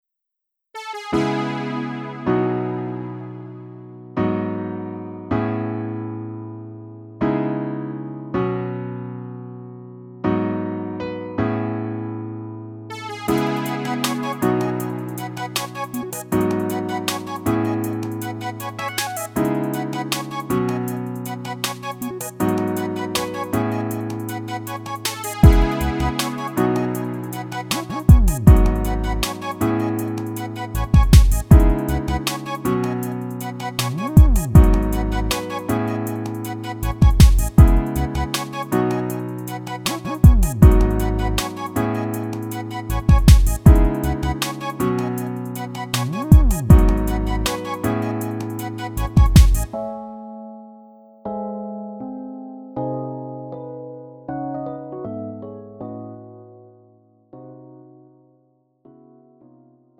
장르 가요 구분 Lite MR
Lite MR은 저렴한 가격에 간단한 연습이나 취미용으로 활용할 수 있는 가벼운 반주입니다.